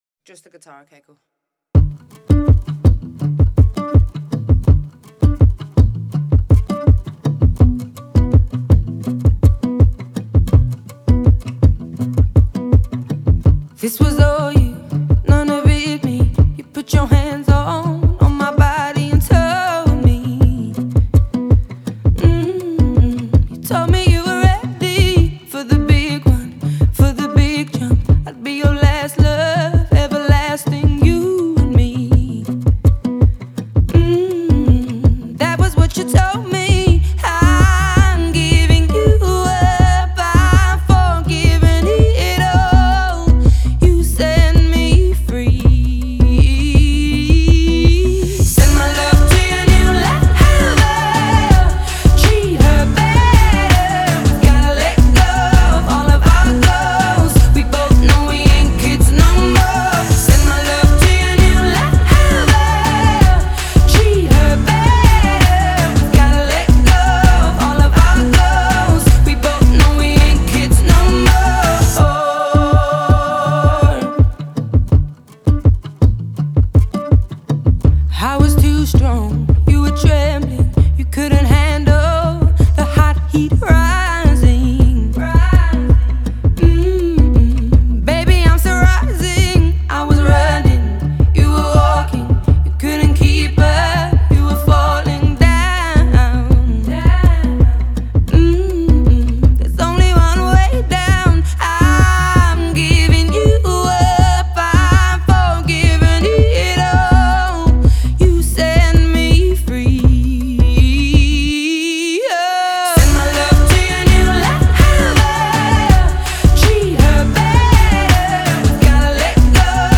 Genre: Pop,Blues